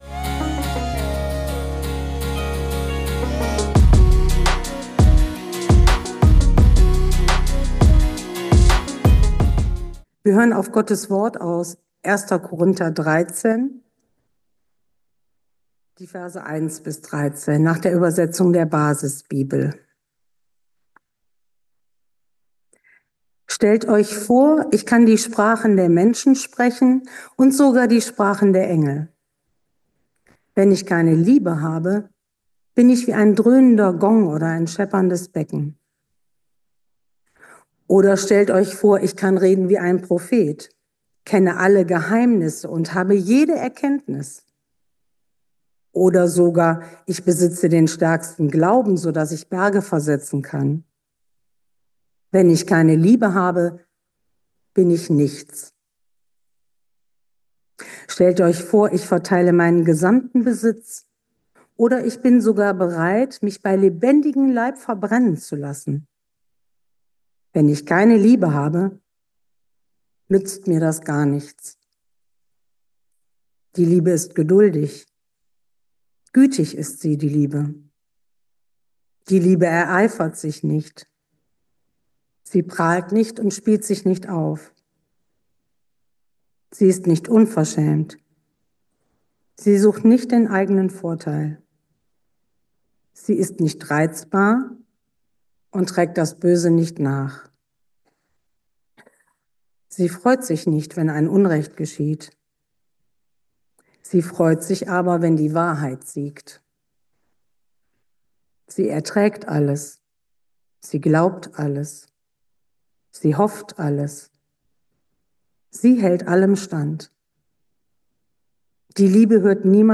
Predigt zur Jahreslosung 2024 am Jahresende